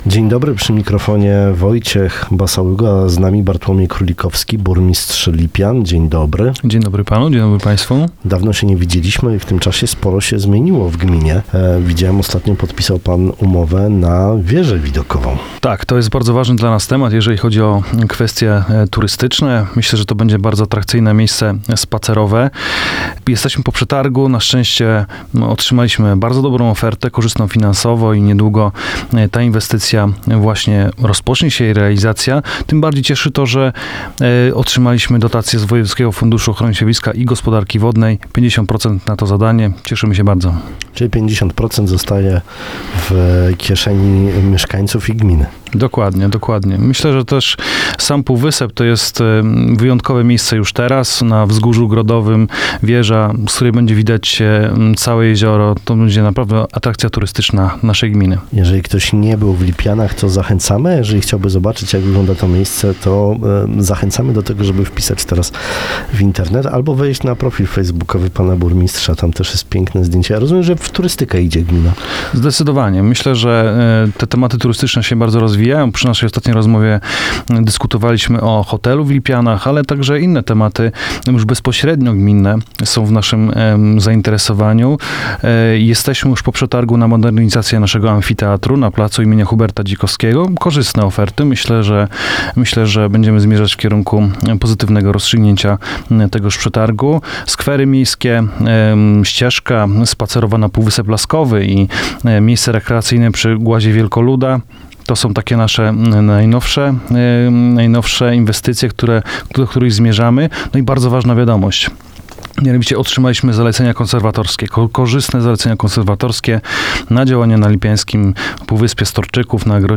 Burmistrz Bartłomiej Królikowski był w piątek Gościem Rozmowy Dnia w Twoim Radiu. Mówił także o kolejnych miejscach do wypoczynku jakie powstaną w najbliższym czasie w gminie Lipiany, a także o planach inwestycyjnych i remontach w sołectwach.